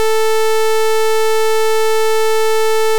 OPL waveforms
Pulse-sine, a.k.a. pseudo-sawtooth. Waveform 3 (1⁄4 sine alternating with equal silence). Scaling PD variation. Zoom out 2x for the "on/off sine", zoom in 4x after (changing only the non-padding part).